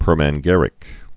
(pûrmăn-gănĭk, -măng-)